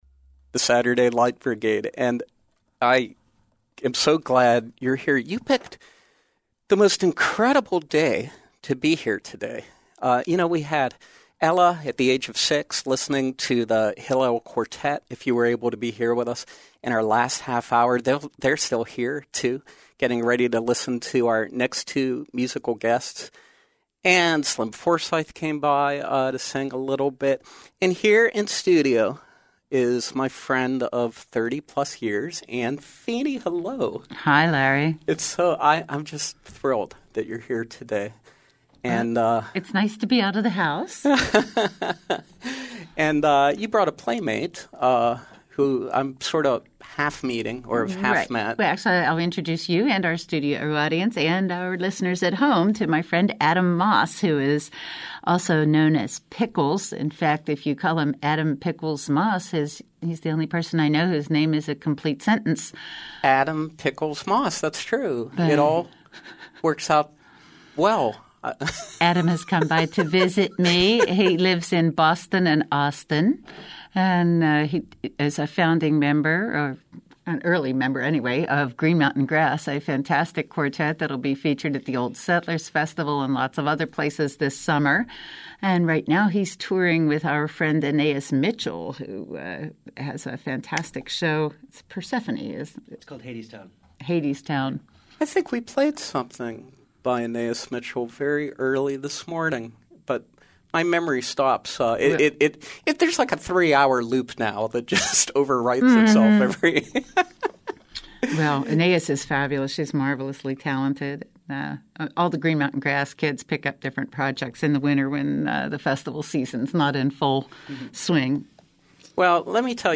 Join us as she performs live in our studios.